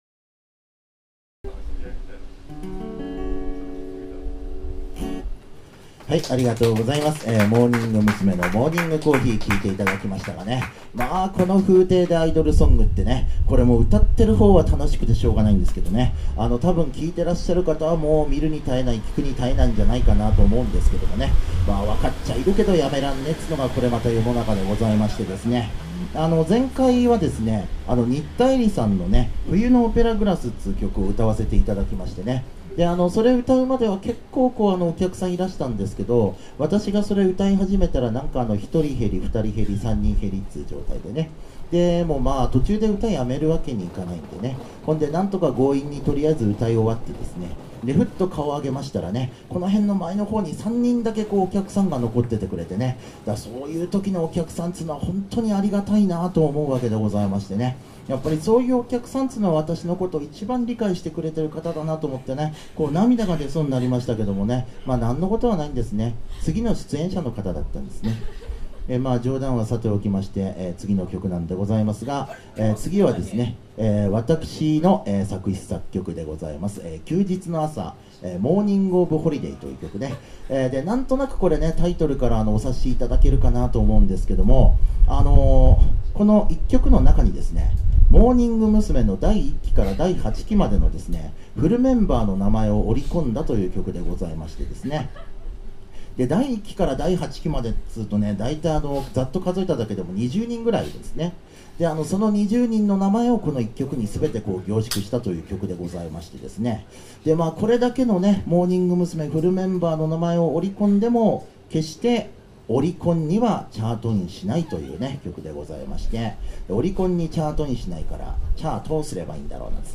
初心に帰って、なごみま鮮果「縁側ライブ」、神田の街かどお騒がせしております。
いつものとおりテーマを決めての楽しいトークとゆかりの歌のコーナーです。